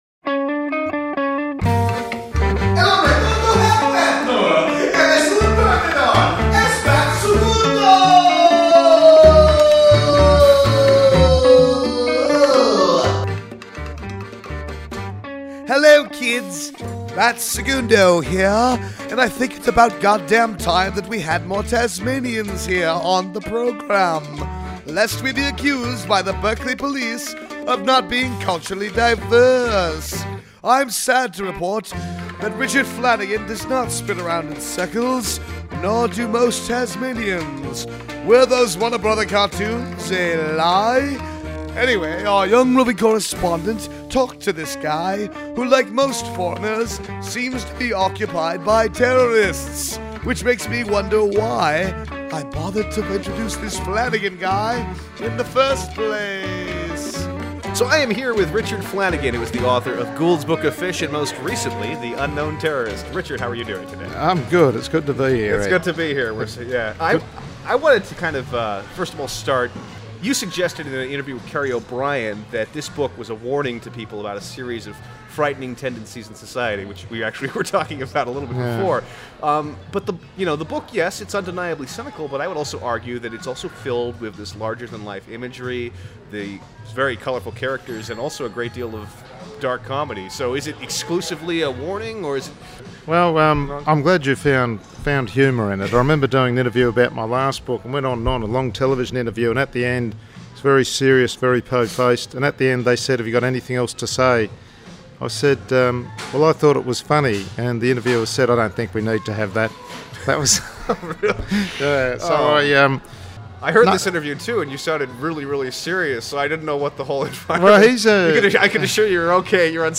BSS #125: Alternative Press Expo 2007, Part Two